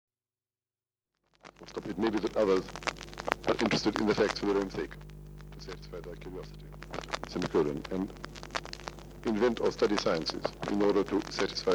Then they had to be massaged through the machine section by section; the stylus used for playback jumped, so that there was repetition, and there were gaps. After what seemed like endless delay and suspense, conventional cassette copies of the extremely crackly sound on the belts were produced.